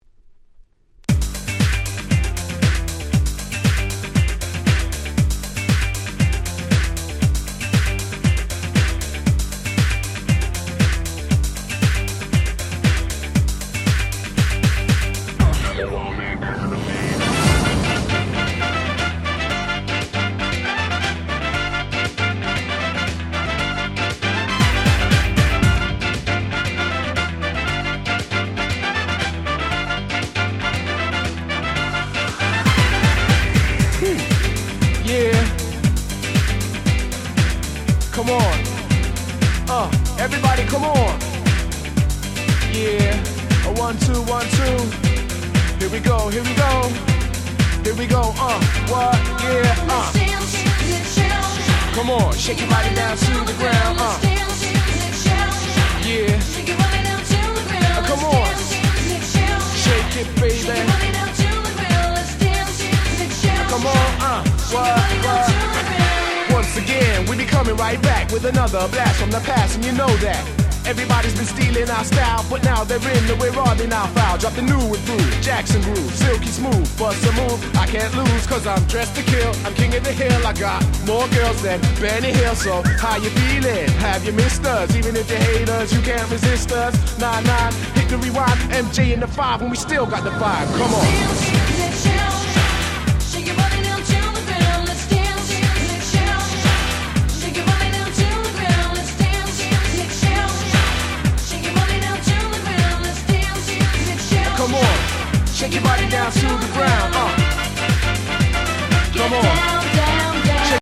00' Nice Dance Pop / キャッチーR&B !!
レアで音質バッチリな国内プロモ！！